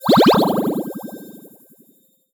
potion_bubble_effect_brew_07.wav